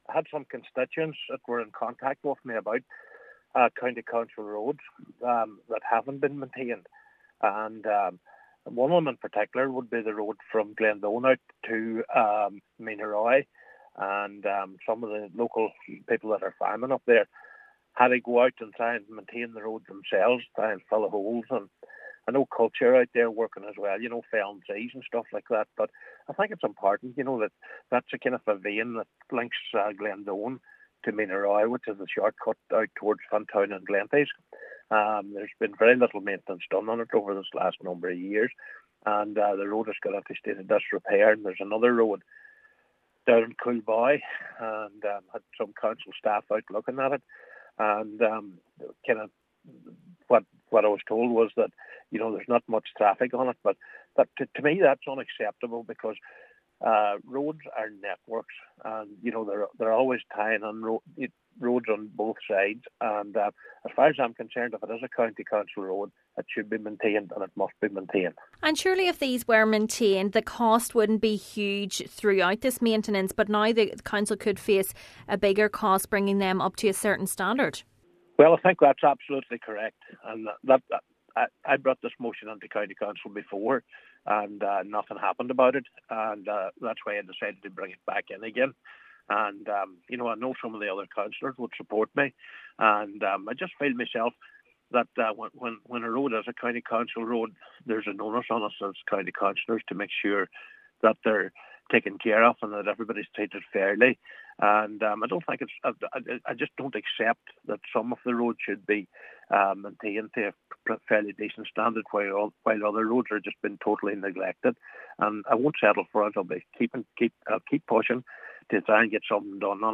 He says this is an unacceptable approach: